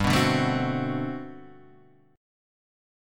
G Major 11th